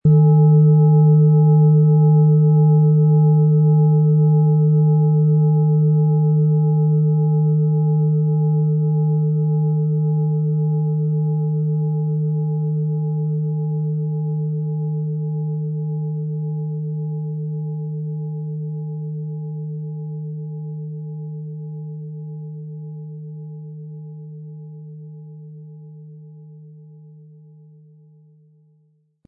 Planetenklangschale Venus Frequenz: 221,23 Hz
Planetenklangschale Venus
Venus-Ton: Frequenz: 221,23 Hz Ton nahe: A Die Lieferung erfolgt inklusive passendem Klöppel, der gut zur Planetenschale passt und diese wundervoll und wohlklingend ertönen lässt.